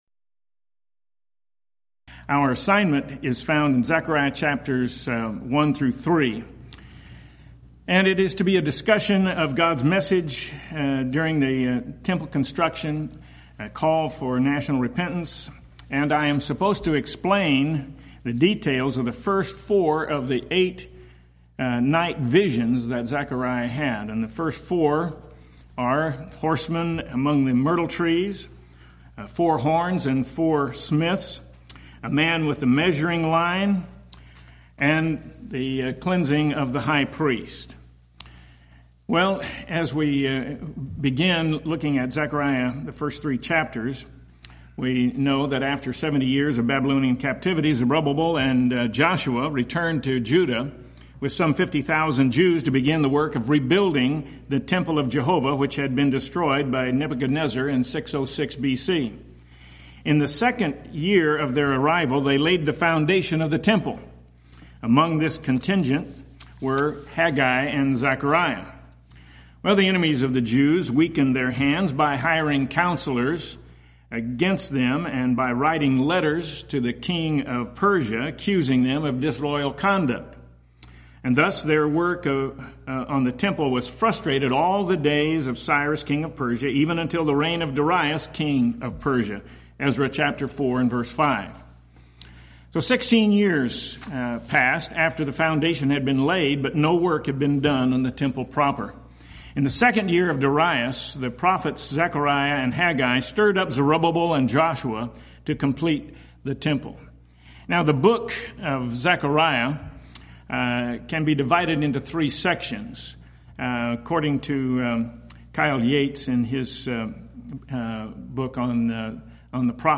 Event: 12th Annual Schertz Lectures Theme/Title: Studies in the Minor Prophets